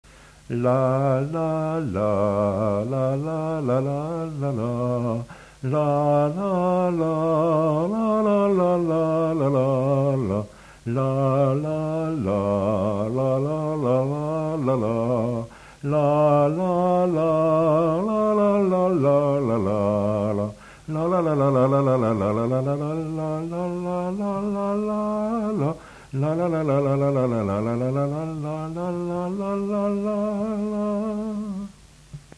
Marche de cortège de noces